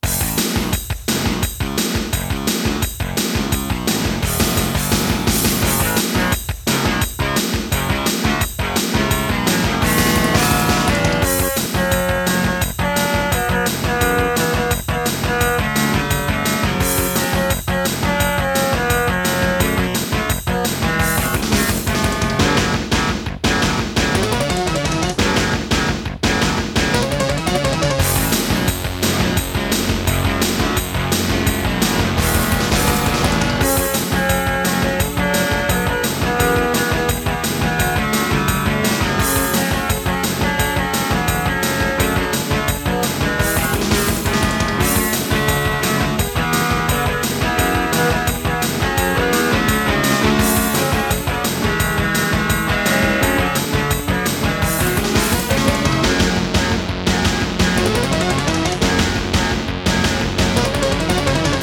Terratec WaveSystem SIWT-1
* Some records contain clicks.